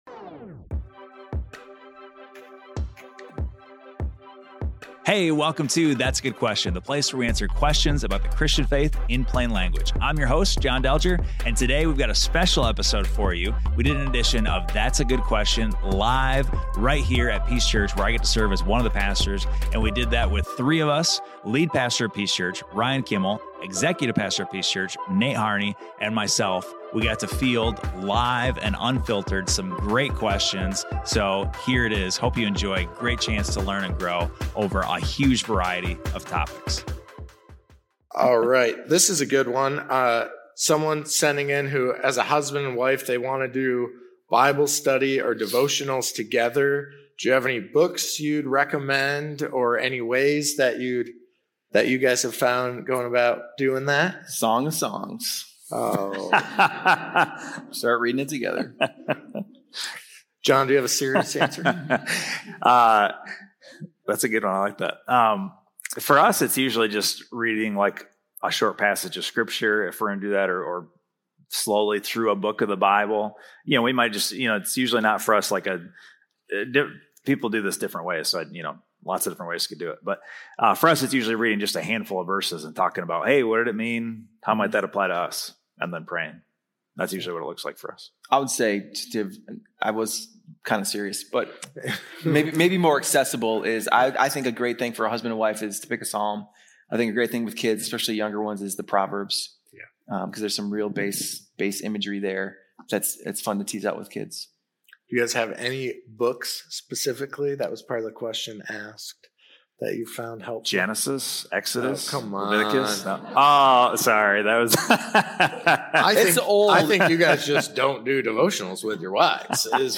This live conversation invites you to rest in what’s unchanging, to walk with boldness, and to live with the quiet strength that comes from being rooted in the gospel.